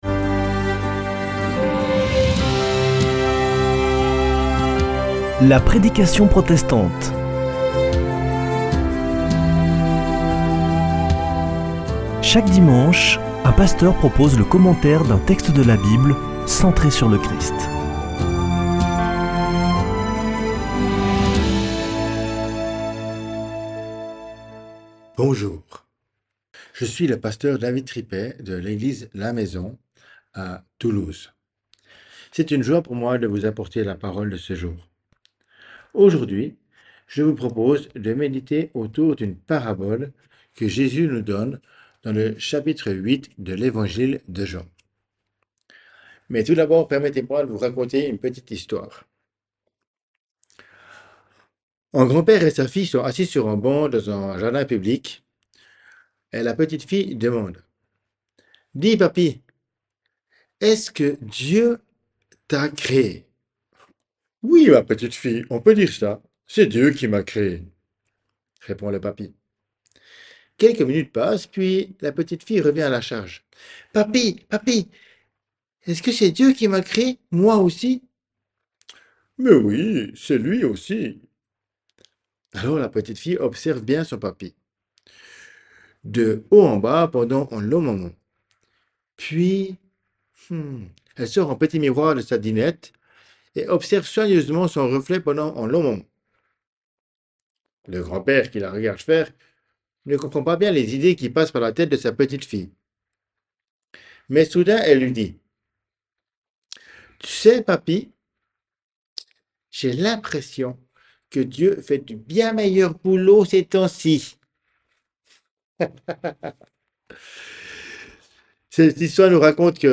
La prédication protestante
Partager Copier ce code (Ctrl+C) pour l'intégrer dans votre page : Commander sur CD Une émission présentée par Des protestants de la région Présentateurs Voir la grille des programmes Nous contacter Réagir à cette émission Cliquez ici Qui êtes-vous ?